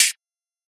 UHH_ElectroHatB_Hit-08.wav